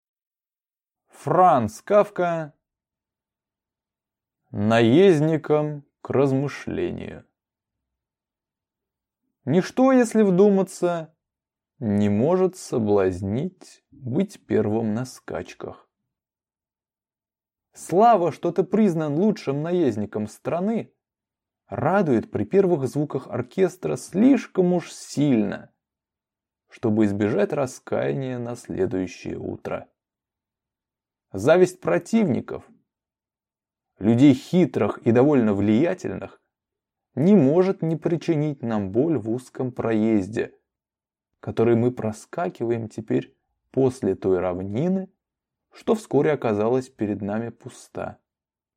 Аудиокнига Наездникам к размышлению | Библиотека аудиокниг